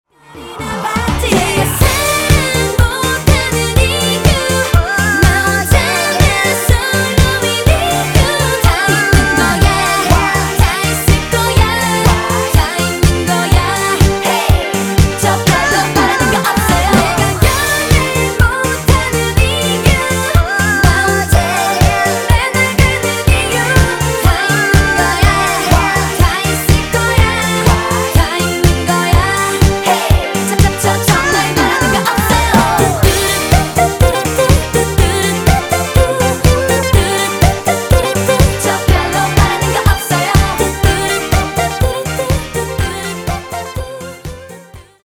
• Качество: 320, Stereo
женский вокал
веселые
заводные
попса
K-Pop
Корейская поп группа